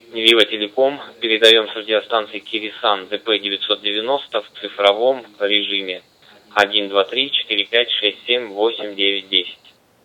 Профессиональная носимая радиостанция с дисплеем и клавиатурой.
Модуляция в цифровом режиме DMR:
kirisun-dp990-tx-dmr.wav